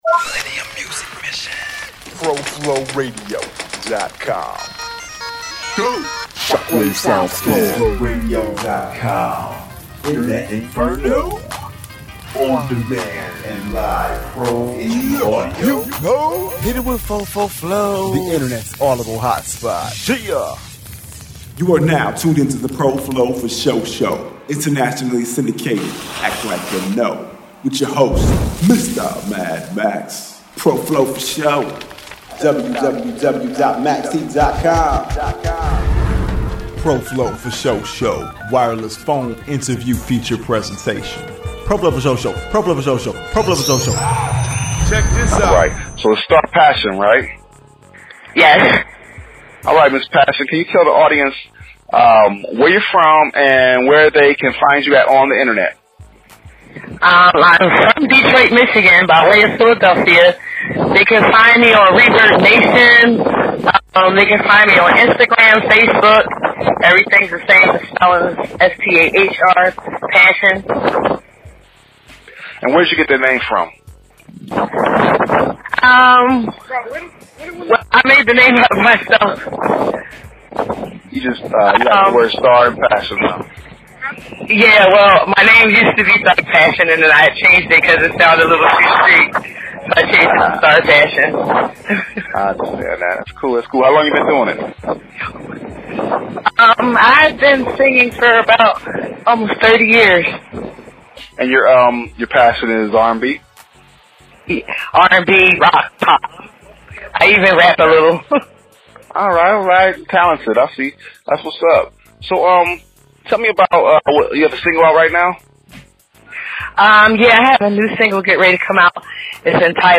Official Interview